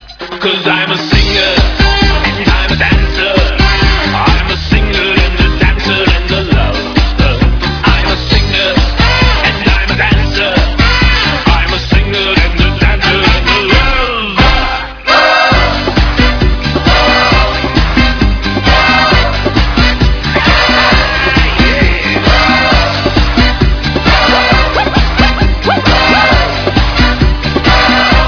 Latino-influenced